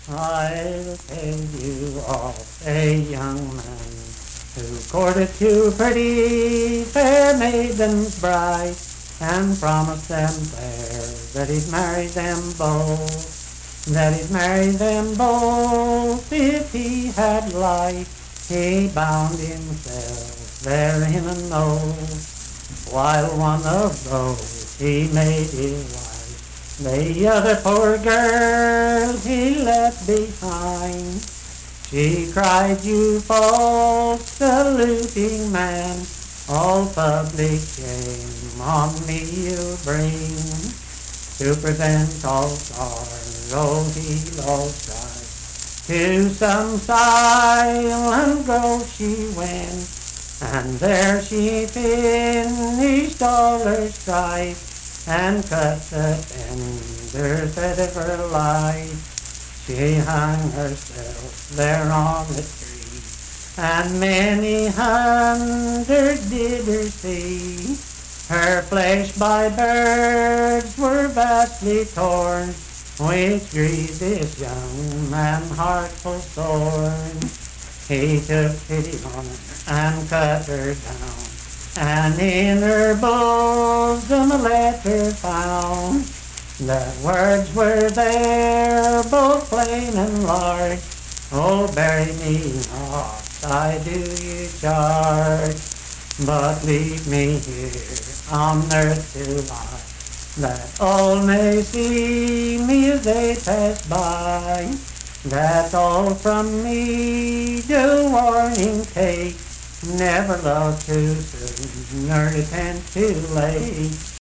Unaccompanied vocal music
Verse-refrain 6(4). Performed in Dryfork, Randolph County, WV.
Voice (sung)